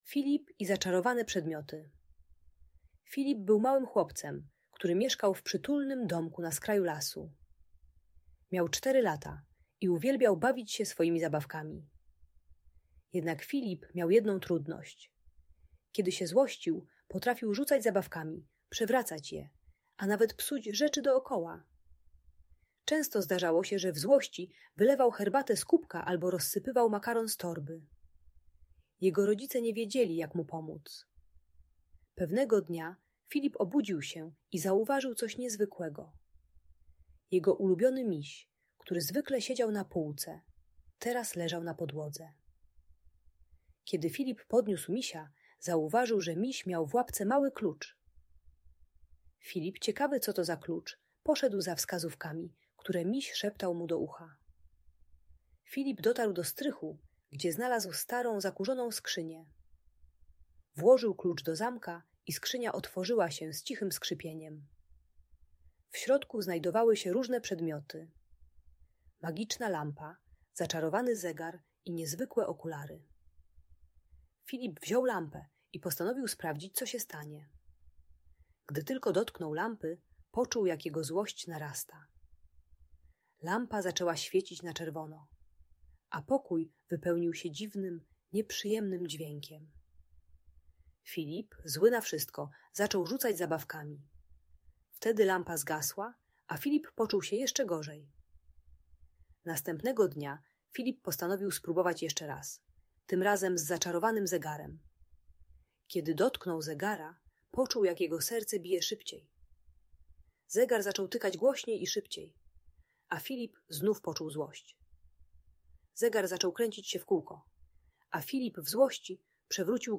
Filip i zaczarowane przedmioty - Bunt i wybuchy złości | Audiobajka